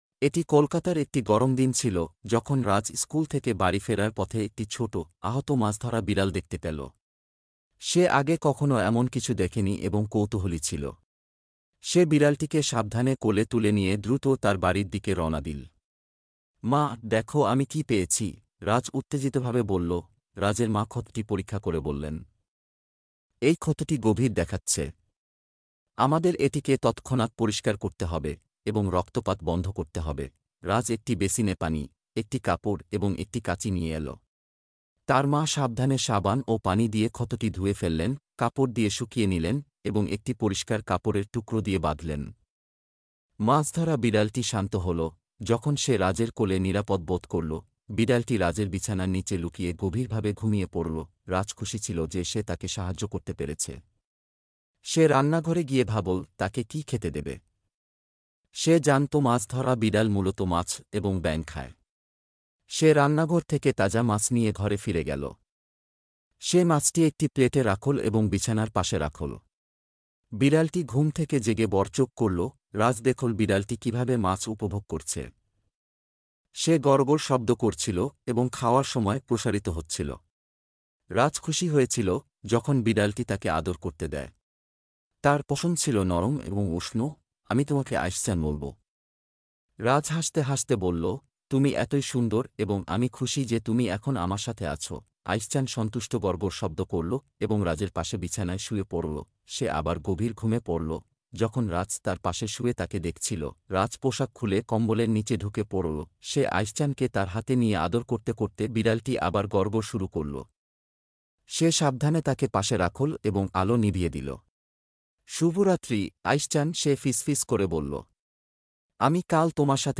Erstellt mit KI: Übersetzung und als Sprachdatei